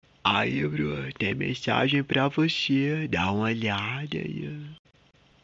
Categoria: Toques